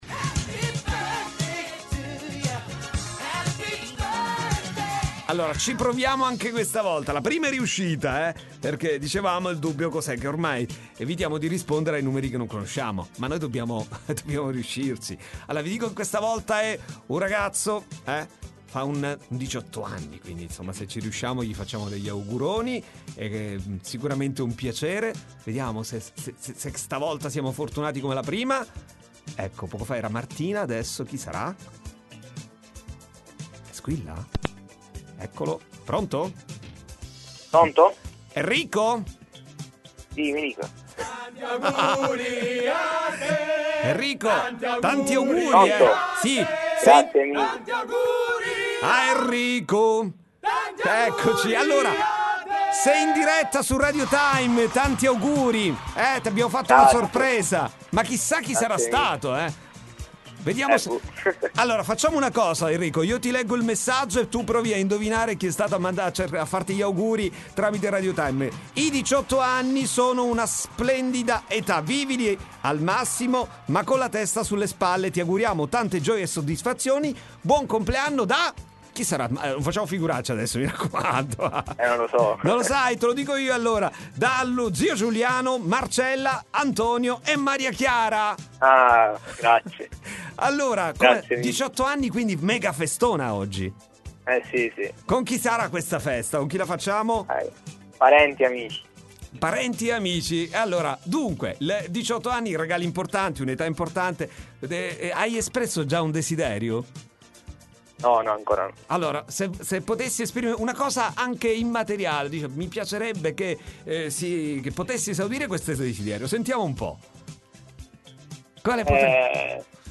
fast n curios Interviste radio time